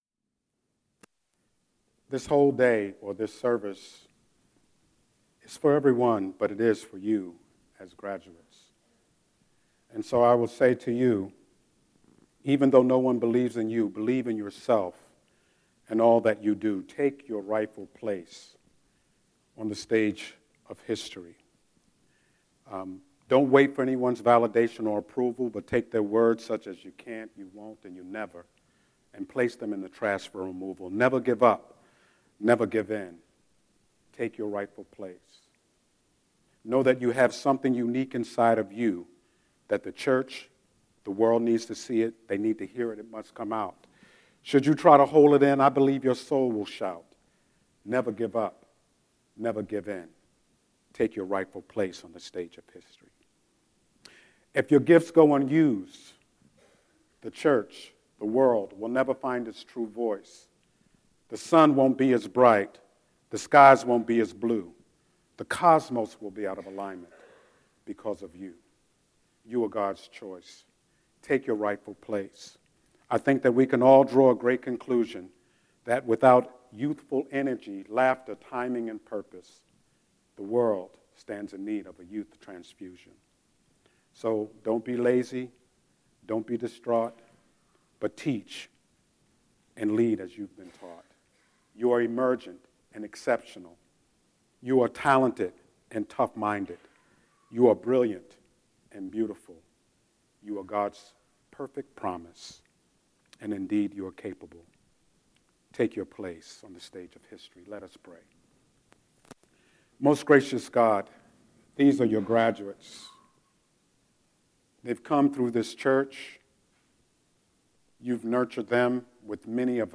Graduation Sunday
17:24 Bulletin Listen to this week’s Scripture and Sermon Posted in
06-05-Scripture-and-Sermon.mp3